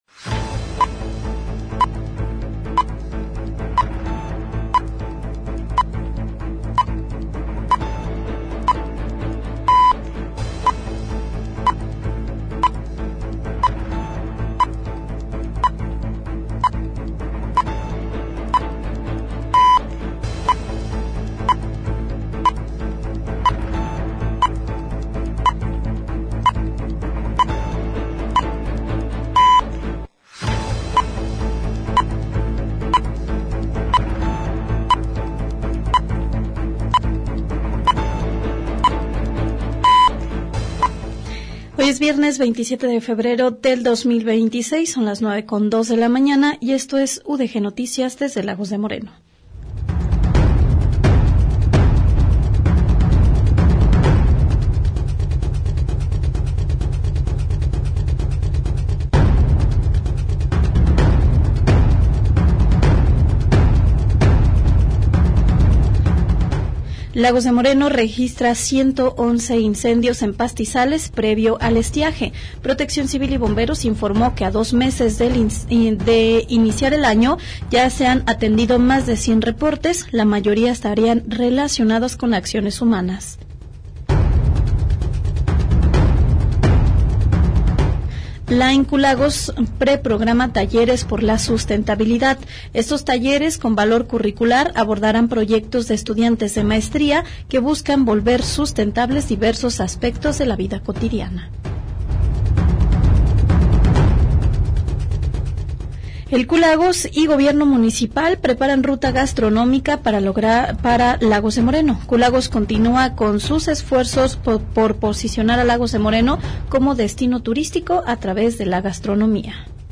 Espacio periodístico dedicado a recopilar, analizar y difundir los acontecimientos más relevantes de una comunidad específica. Ofrece cobertura puntual de los hechos más importantes a nivel local y regional.